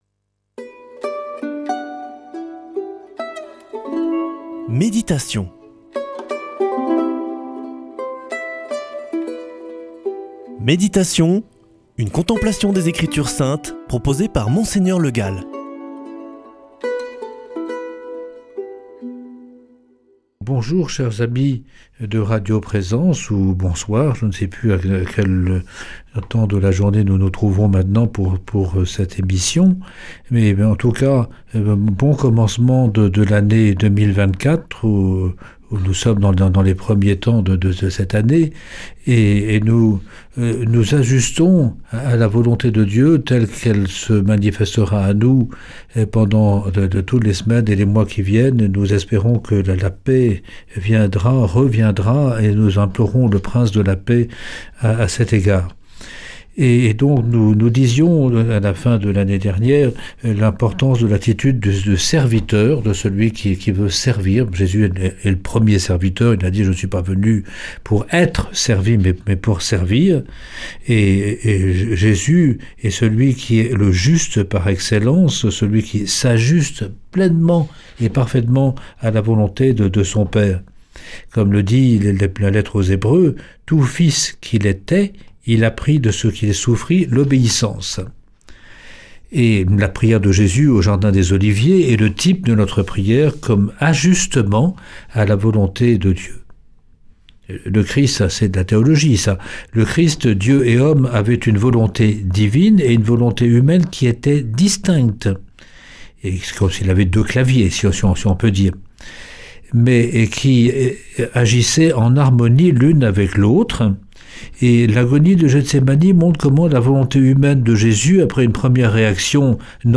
Méditation avec Mgr Le Gall
Une émission présentée par